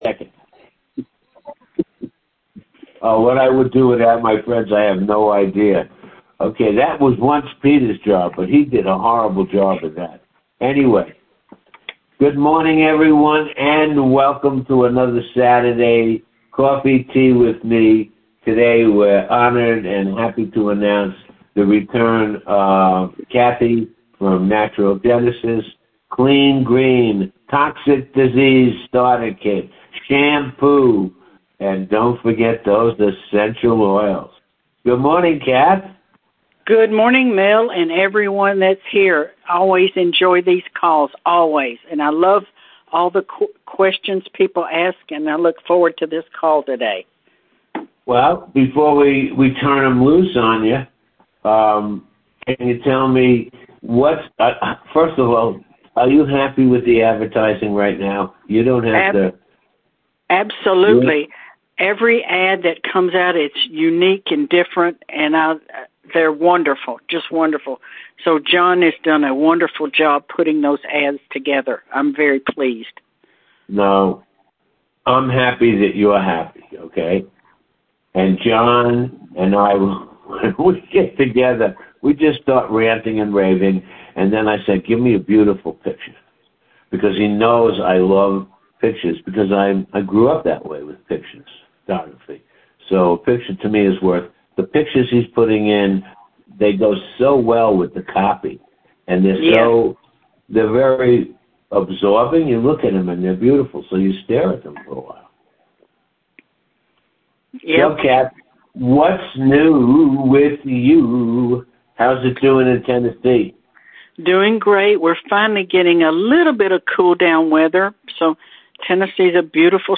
CLICK THE PICTURE FOR A GREAT SAVINGS $ Saturday Conference call (Oct 4th) was a warm gathering with old friends and newbies alike.